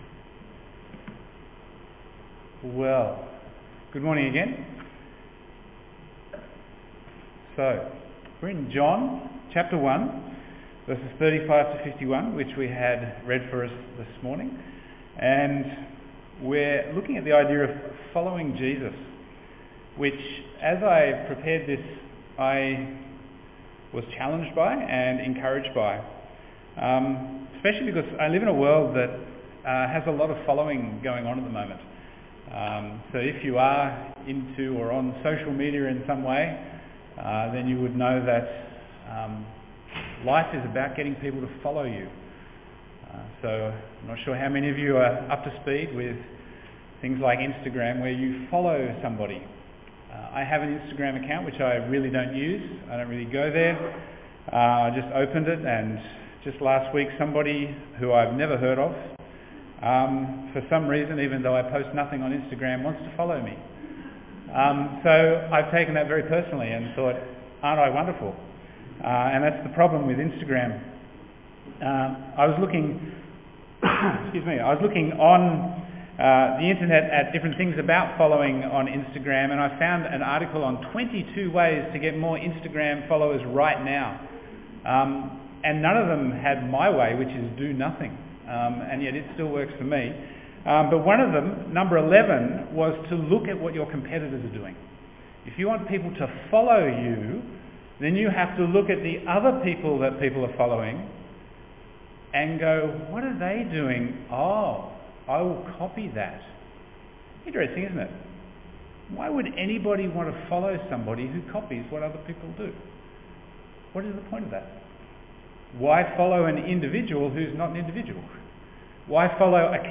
Bible Text: John 1:35-51 | Preacher